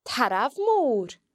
The broad R can also be heard in tarbh (a bull):